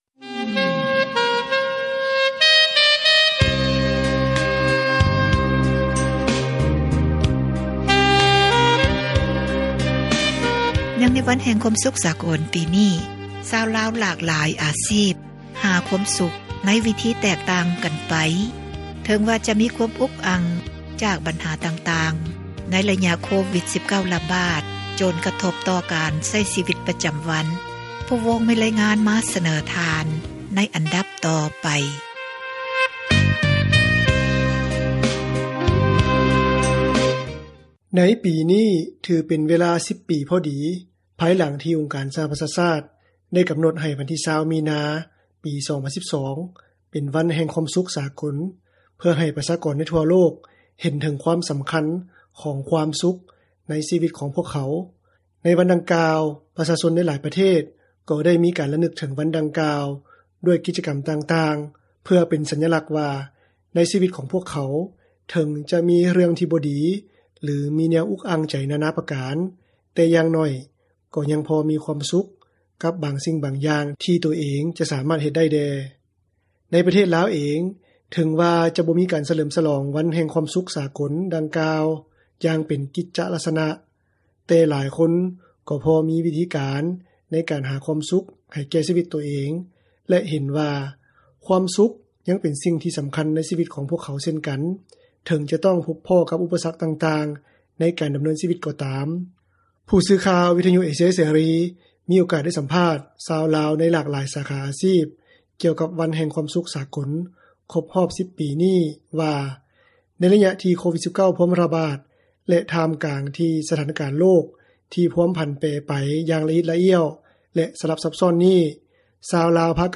ຜູ້ສື່ຂ່າວ ວິທຍຸເອເຊັຽເສຣີ ມີໂອກາດໄດ້ສັມພາດ ຊາວລາວ ໃນຫຼາກຫຼາຍສາຂາອາຊີບ ກ່ຽວກັບວັນແຫ່ງຄວາມສຸຂສາກົລ ຄົບຮອບ 10 ປີນີ້ ວ່າ ໃນໄລຍະທີ່ໂຄວິດ-19 ພວມຣະບາດ ແລະ ຖ່າມກາງທີ່ ສະຖານະການໂລກ ທີ່ພວມຜັນແປໄປ ຢ່າງລະອິດລະອ້ຽວ ແລະ ສລັບຊັບຊ້ອນນີ້ ຊາວລາວ ພາກັນຫາຄວາມສຸຂໄດ້ ດ້ວຍວິທີໃດແດ່ ແລະ ຄຽງຄູ່ກັບຄວາມສຸຂນັ້ນ ມີເຣື່ອງໃດແດ່ ທີ່ພວກເຂົາພາກັນອຸກໃຈ.